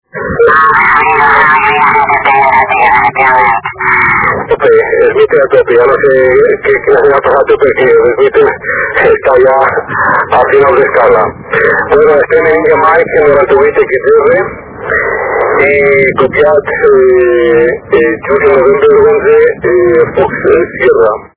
Archivos sonido de QSOs en 10 GHz SSB
398 Kms Tropo Mar